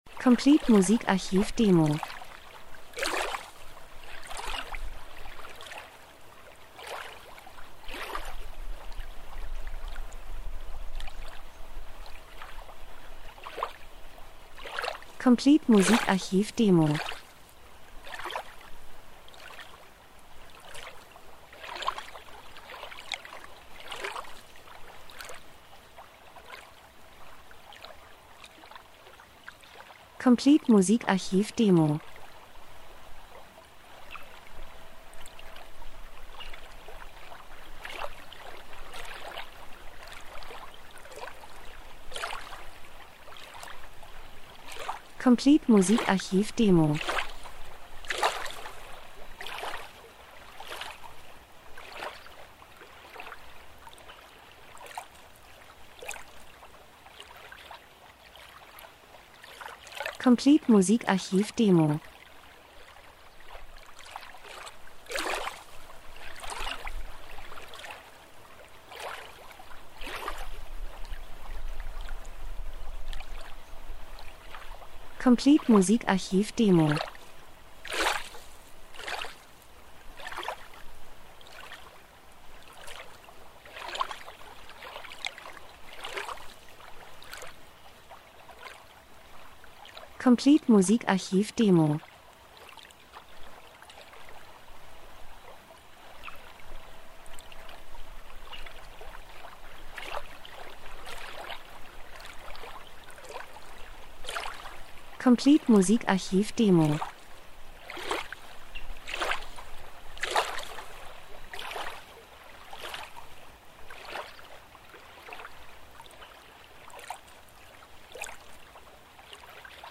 Sommer -Geräusche Soundeffekt Natur Wellen See 02:07